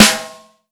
• Verby Acoustic Snare Sample G# Key 409.wav
Royality free steel snare drum sample tuned to the G# note. Loudest frequency: 3462Hz
verby-acoustic-snare-sample-g-sharp-key-409-80b.wav